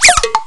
Sons de humor 47 sons
whizbonk.wav